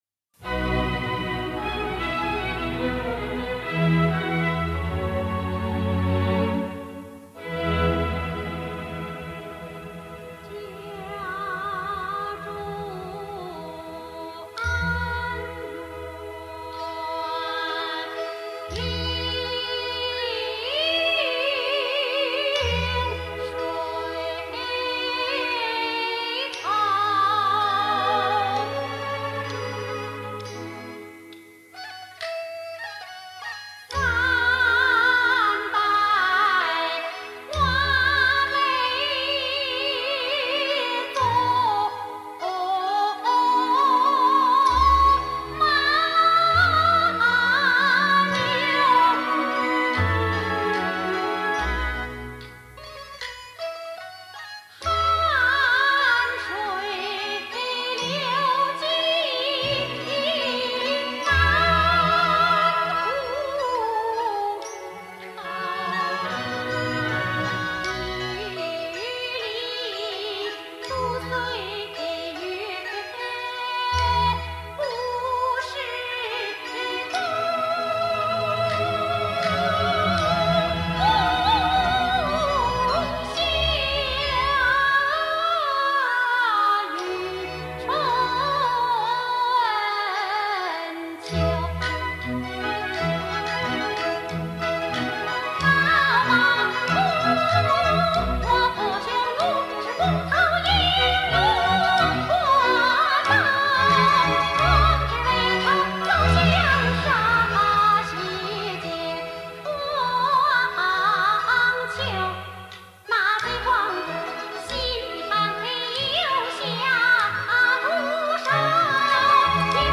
京胡
板鼓
京二胡
月琴